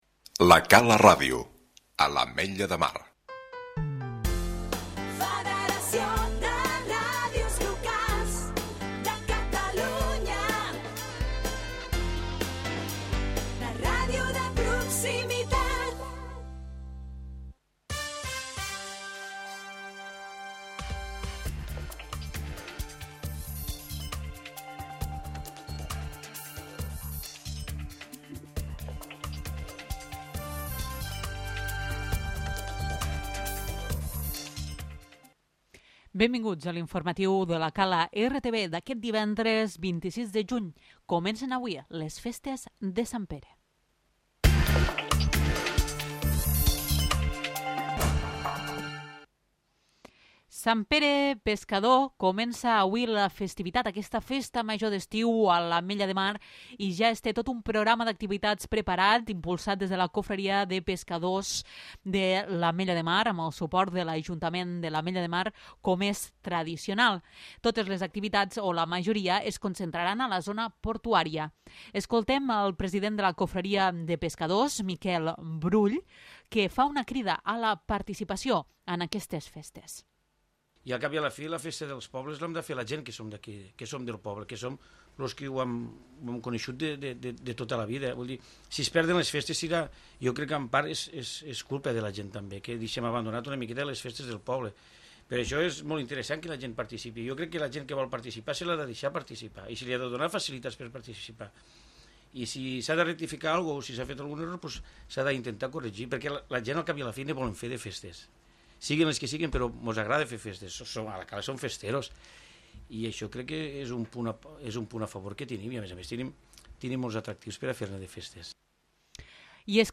Butlletí informatiu